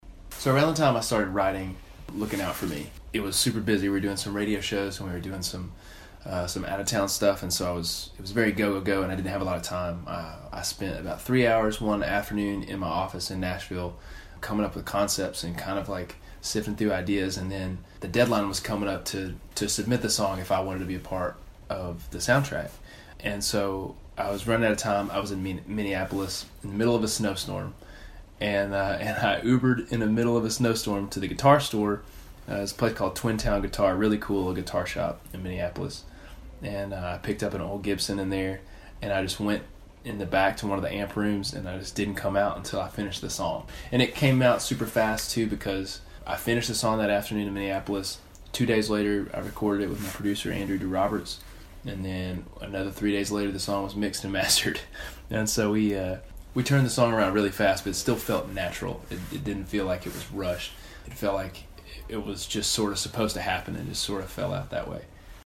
Audio / Adam Hambrick talks about the quick process it took him to write and record “Looking Out for Me,” which will appear of the soundtrack for the new film, Breakthrough.